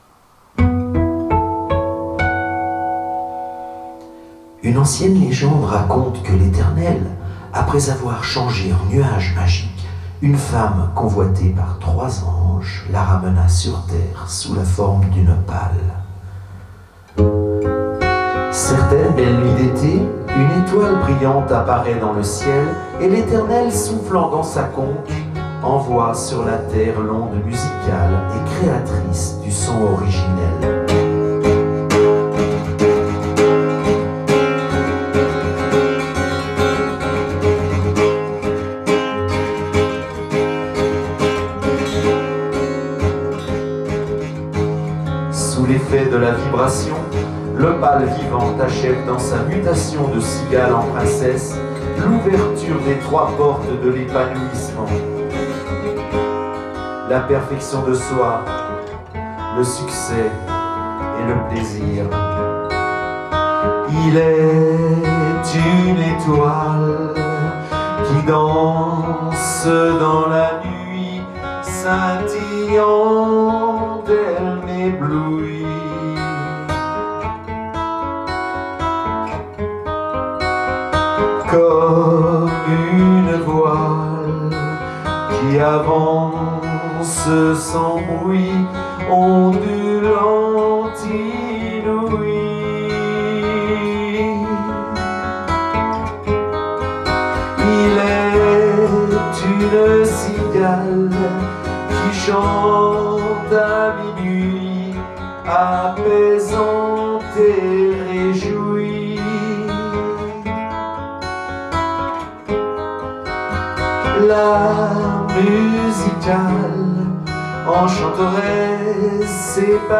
Rumba orientale contant l'histoire de la danseuse charismatique,